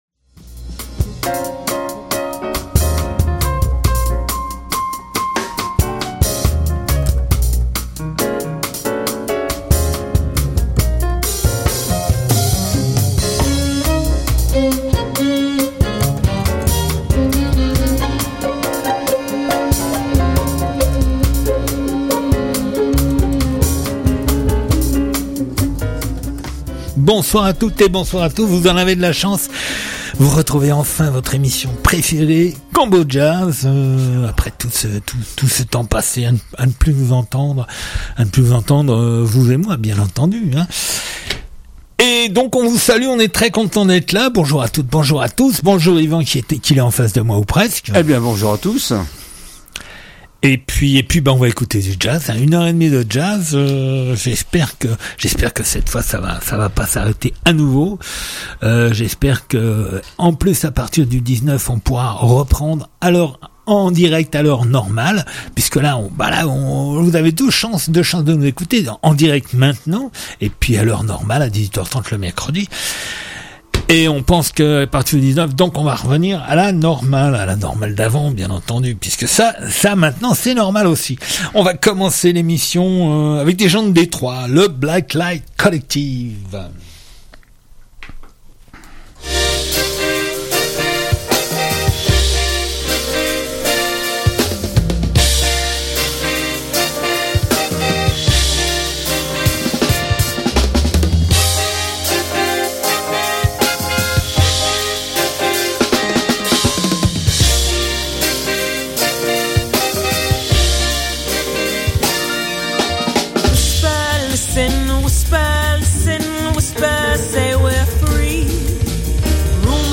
Combojazz du 03 Mai 2021 Lundi 3 Mai 2021 Du jazz rien que du jazz, 1 lundi sur 2 de 16h00 à 17h30 et 1 mercredi sur 2 de 18h30 à 20h00.